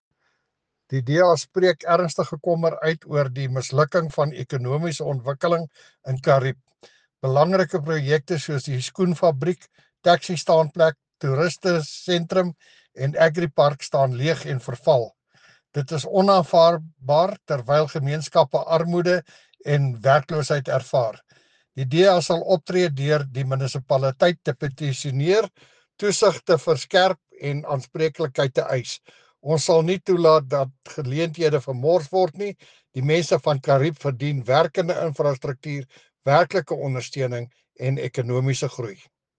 Afrikaans soundbites by Cllr Jacques van Rensburg and